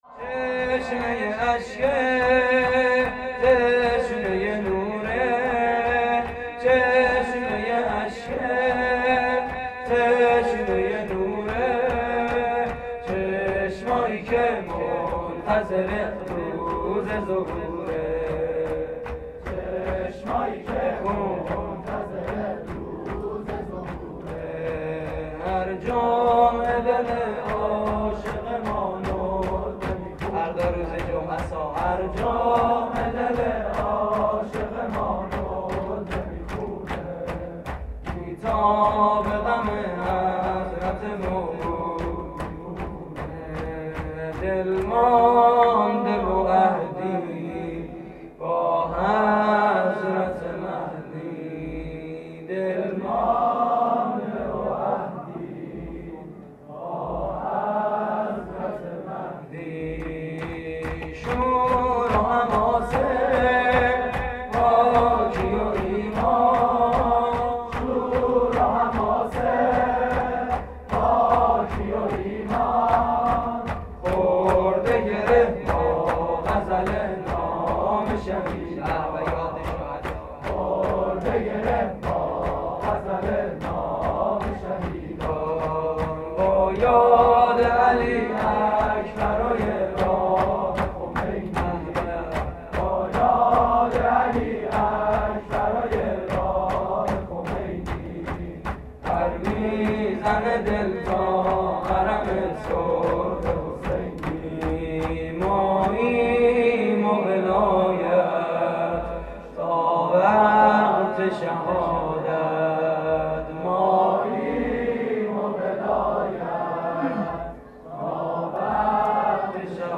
سرود هیئت: چشمه‌ی اشکه
مراسم عزاداری شب ششم محرم 1432